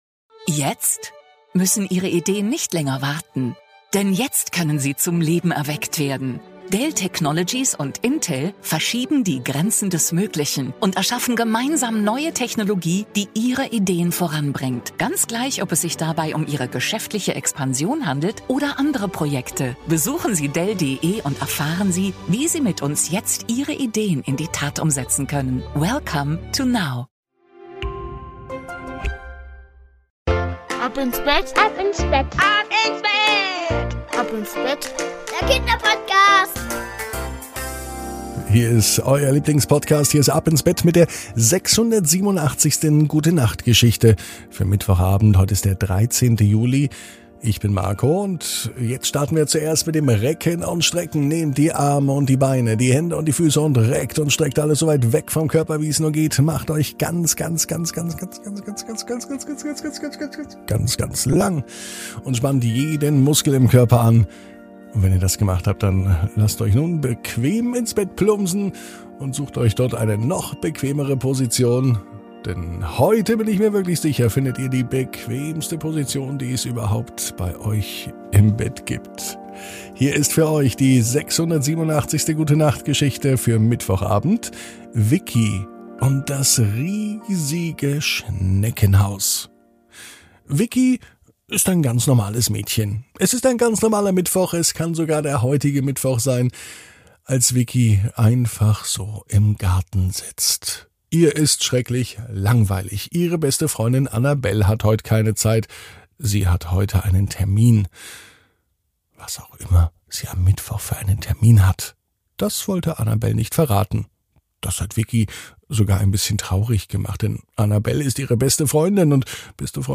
#687 Vicki und das riesige Schneckenhaus ~ Ab ins Bett - Die tägliche Gute-Nacht-Geschichte Podcast